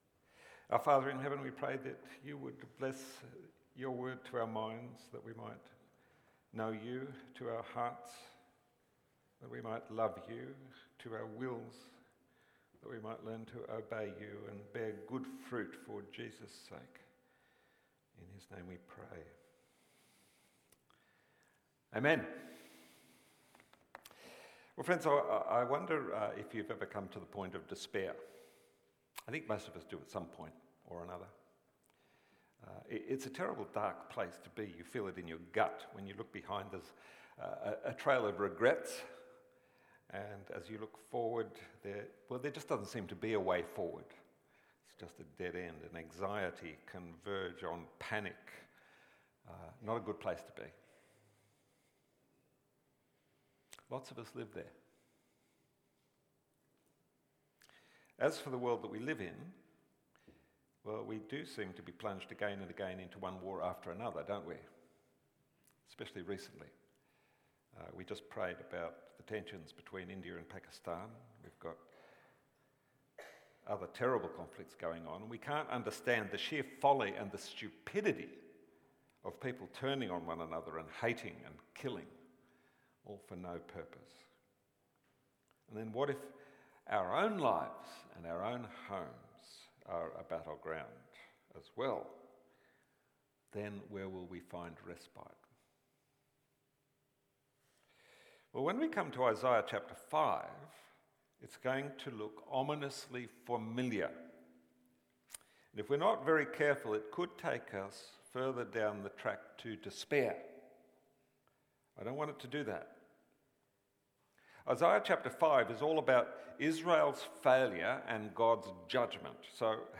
Sermons | St Johns Anglican Cathedral Parramatta
Watch the full service on YouTube or listen to the sermon audio only.